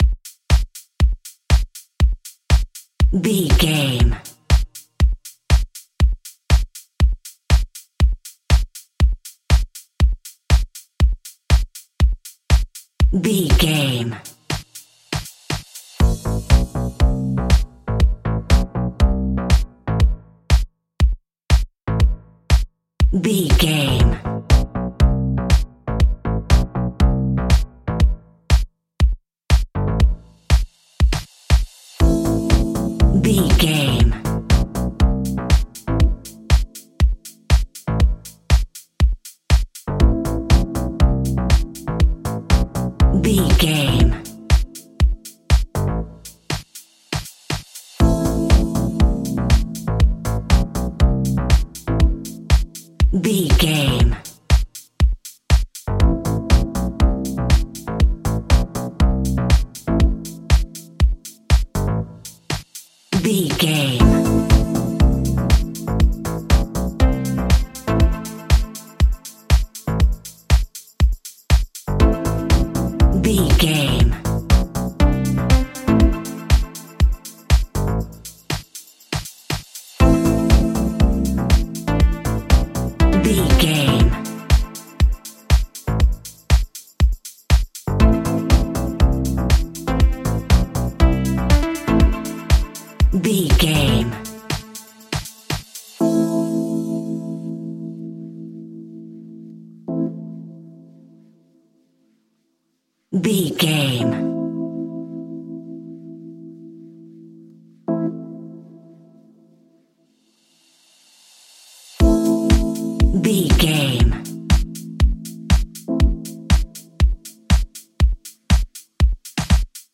Aeolian/Minor
groovy
uplifting
driving
energetic
drums
bass guitar
synthesiser
electric piano
funky house
electro
upbeat
instrumentals